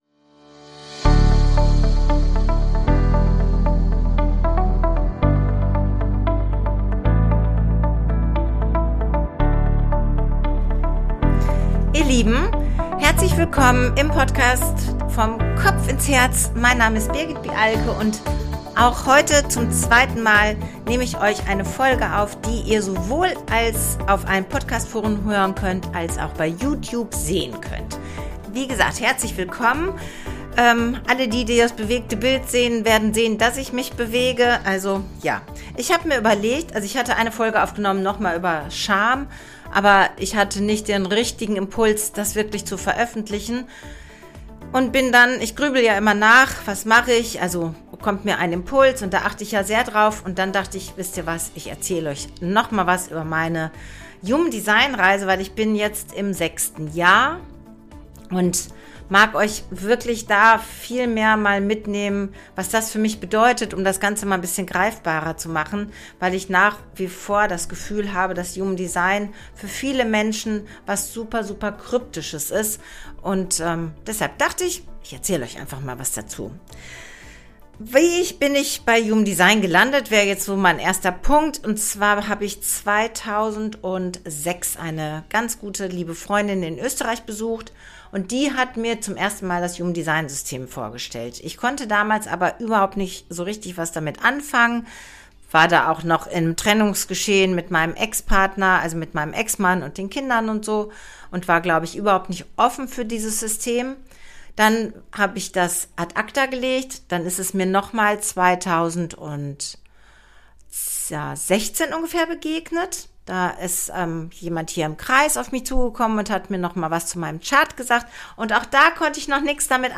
In dieser Folge nehme ich dich mit in meine Human-Design-Reise der letzten sechs Jahre: wie ich zum System gekommen bin und was sich dadurch in meinem Alltag verändert hat – besonders bei Entscheidungen, Grenzen, Beziehungen und Selbstvertrauen. Frei gesprochen, persönlich und alltagsnah.